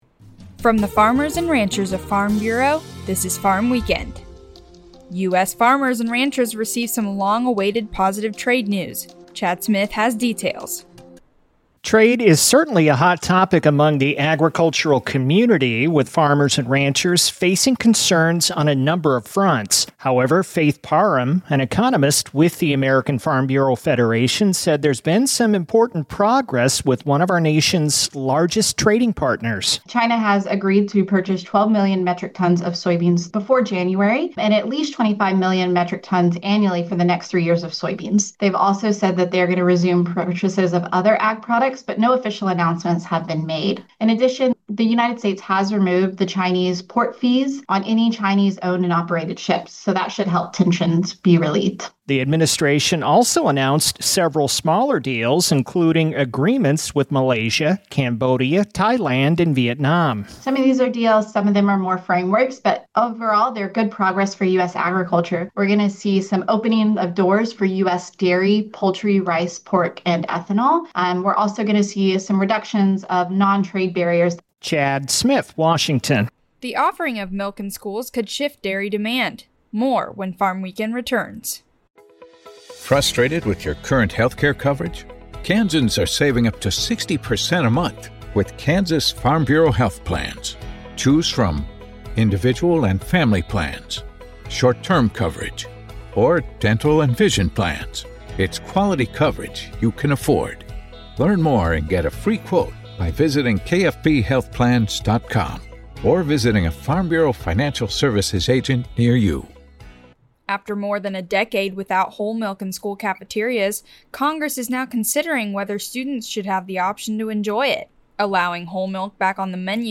A 5-minute radio program featuring a recap of the week's agriculture-related news and commentary.